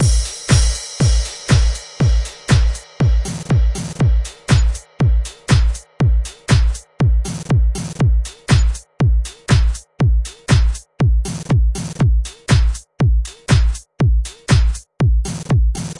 电音合成器循环120 bpm
描述：Electro Groovy Synth Loop 120 bpm。
Tag: 寒意 环路 电子 音乐 循环 合成器 节奏 120-BPM 合成 休息室 合成器环路 环境 声音 节拍 背景 音乐圈 节奏 常规